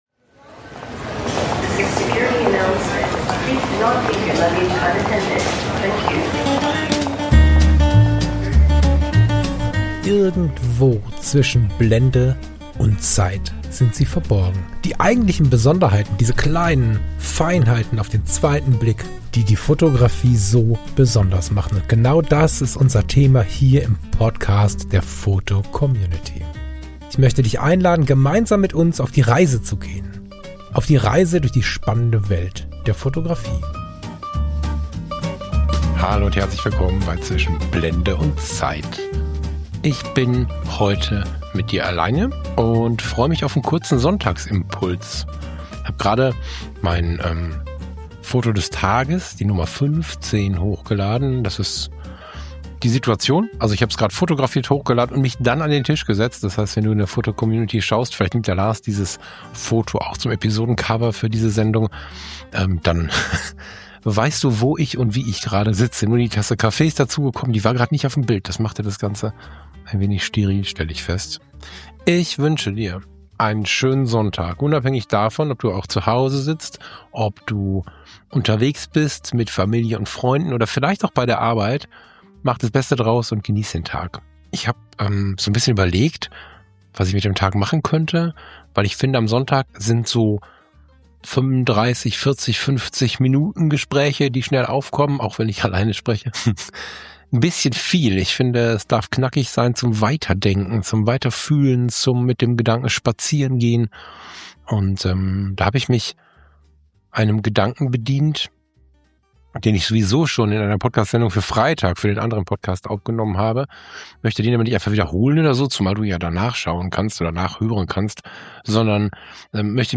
Beschreibung vor 3 Monaten Ein kurzer Sonntagsimpuls – nur Du und ich.